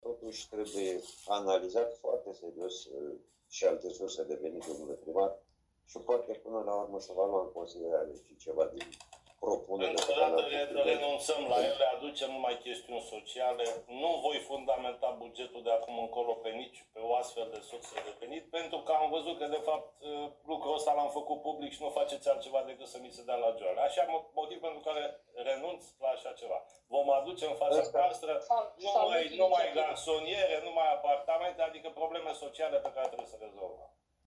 Ședință CLM.